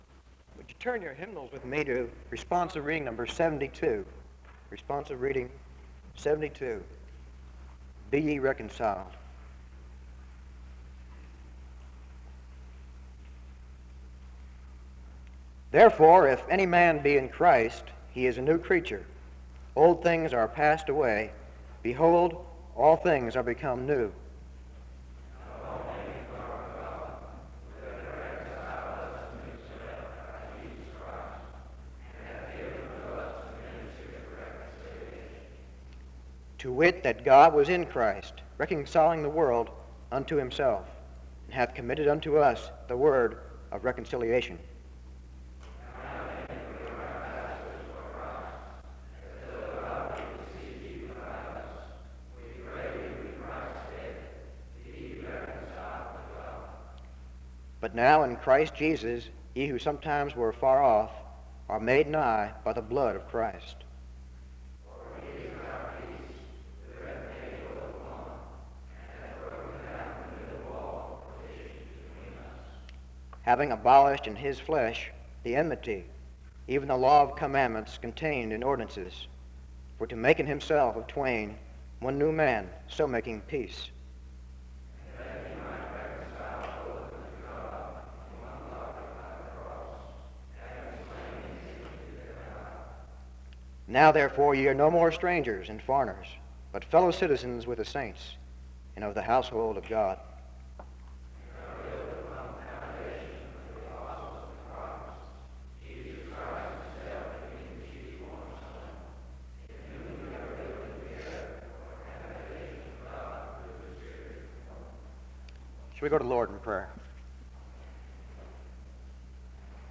The service begins with a responsive reading and prayer (0:00-2:59).
SEBTS Chapel and Special Event Recordings